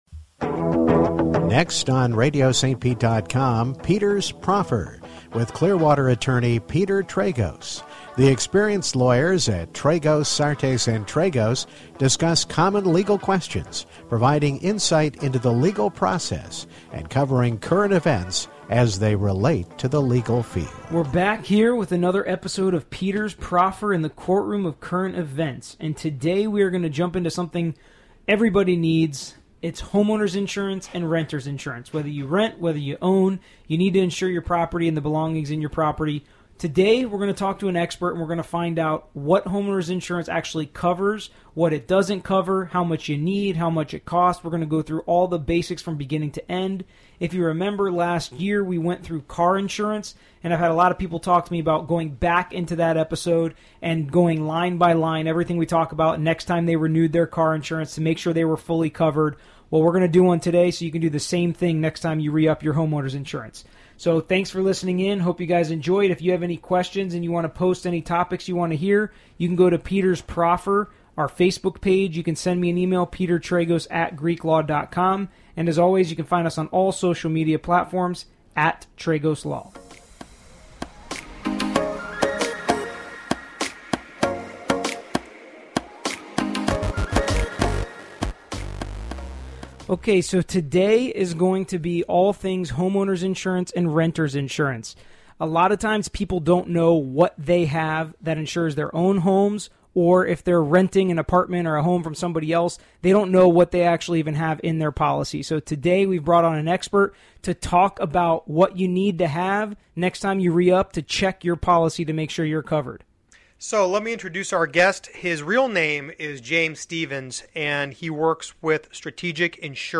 Whether you rent or you own your home, it’s important to insure your personal property against theft and damage. This week, we talk to a Florida insurance expert about what exactly homeowners insurance covers, what it doesn’t, how much you need, how much it costs, and much more.